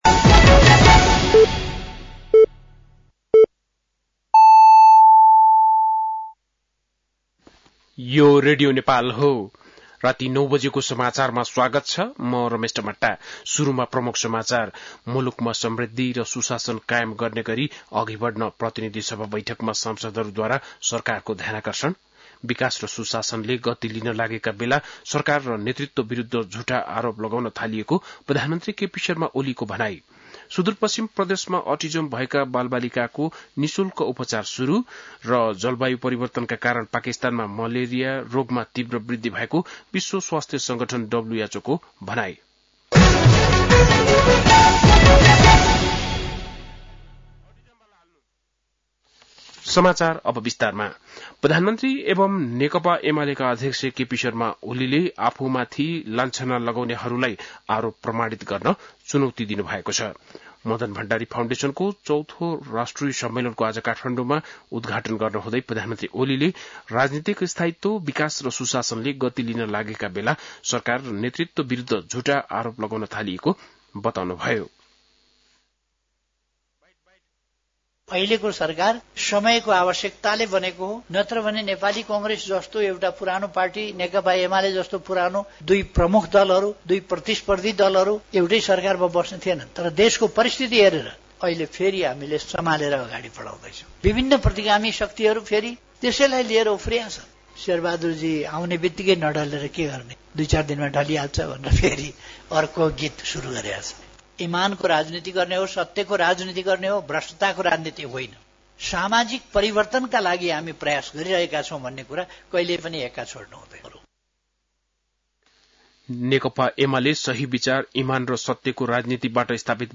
बेलुकी ९ बजेको नेपाली समाचार : १४ वैशाख , २०८२
9-pm-nepali-news-.mp3